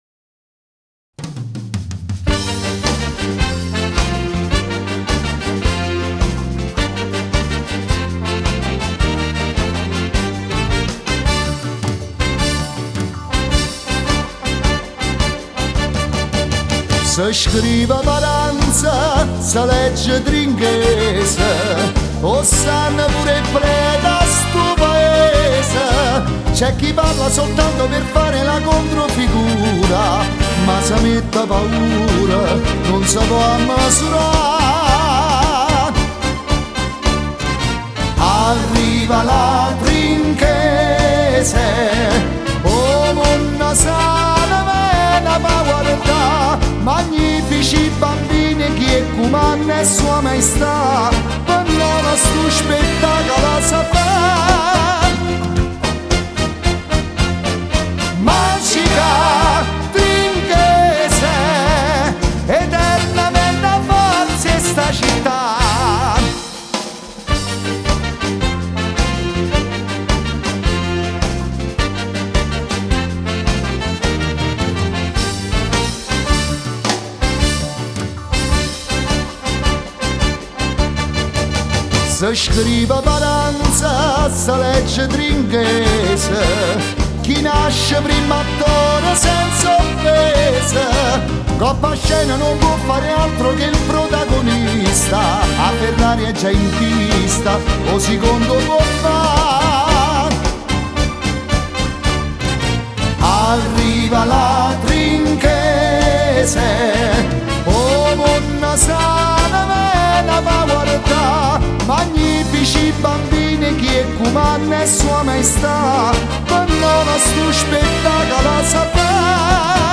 Cantanti